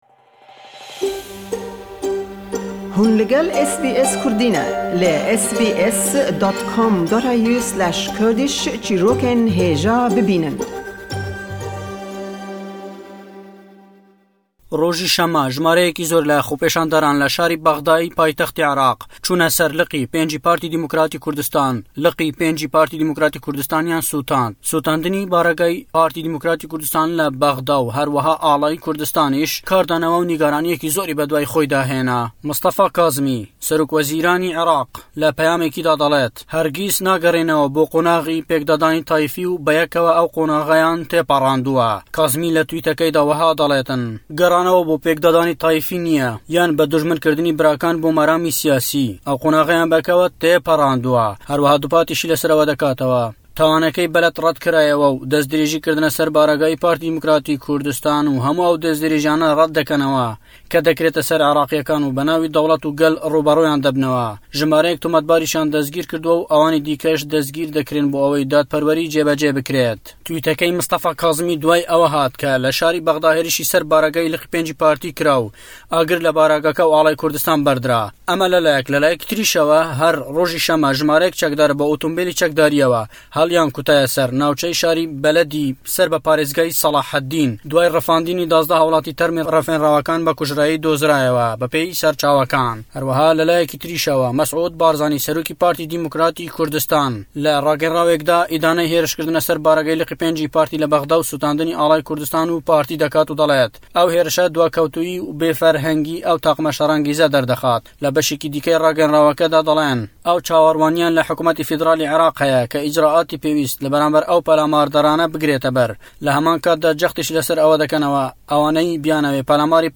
Di raporta ji Hewlêrê de behs li ser êrîşkirina Liqa 5 ya Partiya Demokratî Kurdistan û şewitandina avahî û Ala Kurdistanê li Bexdadê.